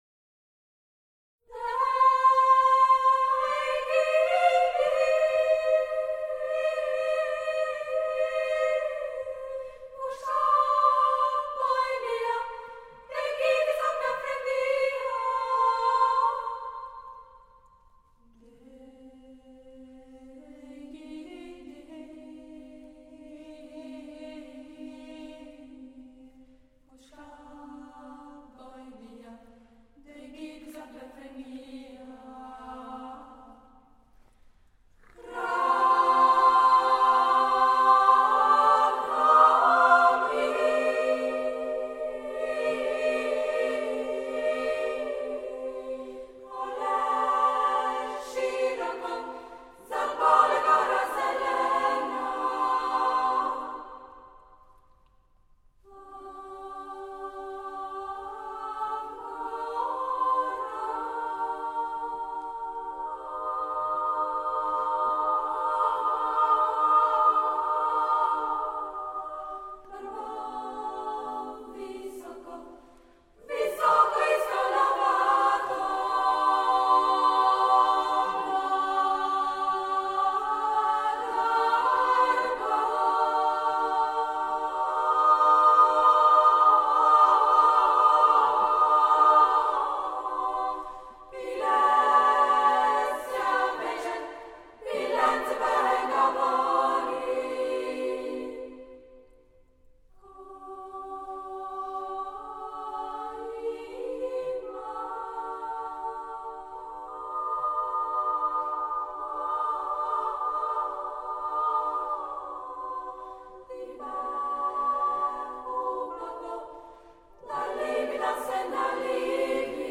Registrato nel 2003 negli Studi RSI a Lugano.